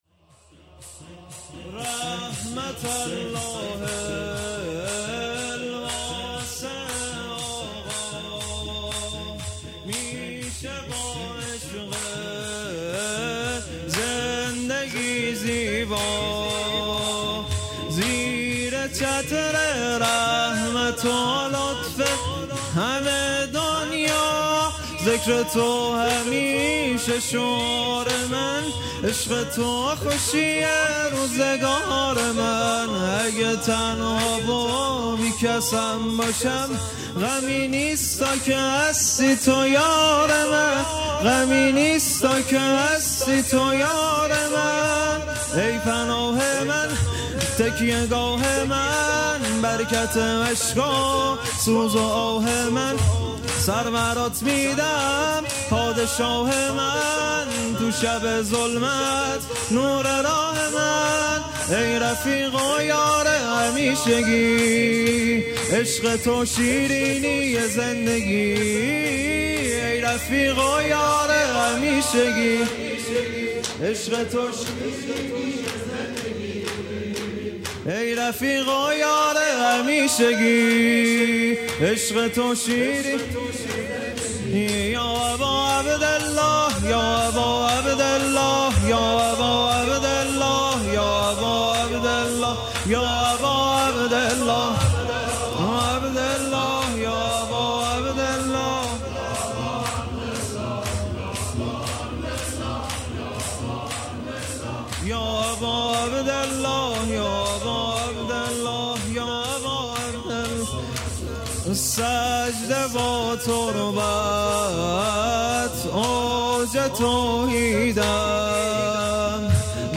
۷ اردیبهشت ۴۰۱ شور مداحی ماه رمضان اشتراک برای ارسال نظر وارد شوید و یا ثبت نام کنید .